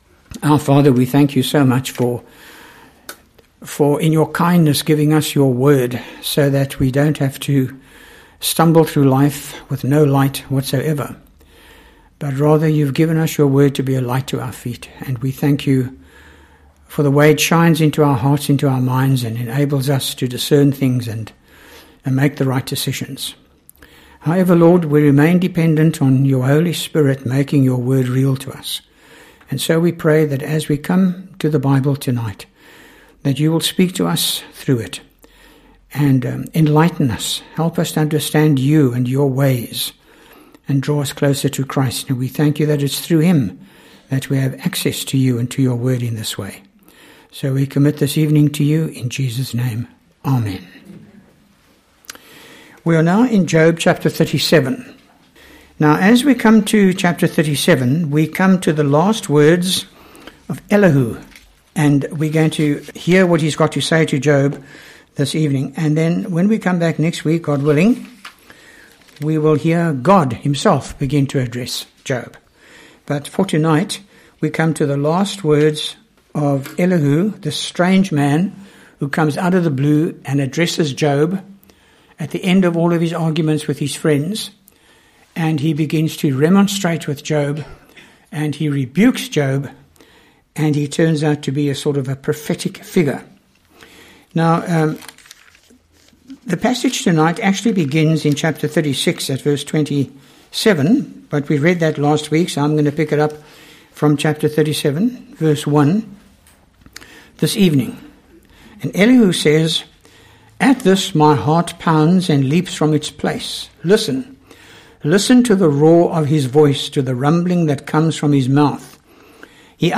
by Frank Retief | Sep 24, 2017 | Job, Sermons